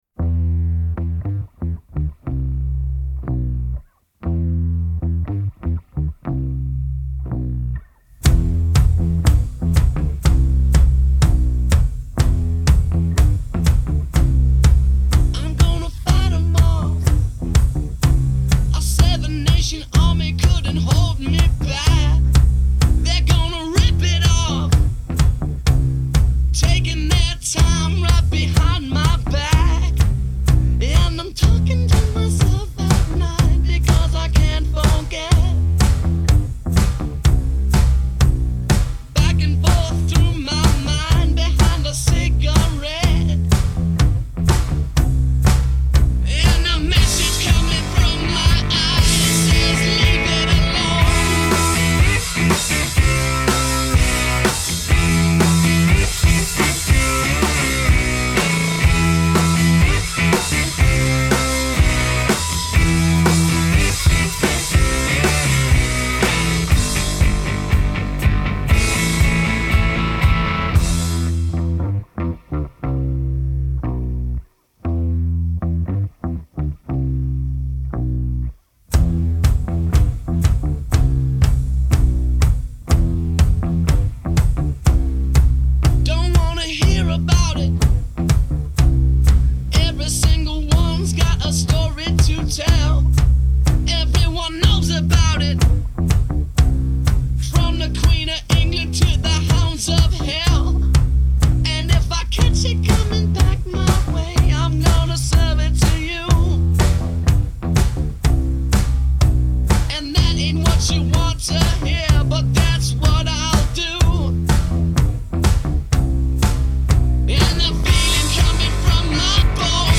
Rock 2000er